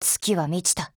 贡献 ） 协议：Copyright，其他分类： 分类:SCAR-H 、 分类:语音 您不可以覆盖此文件。
SCARH_SKILL3_JP.wav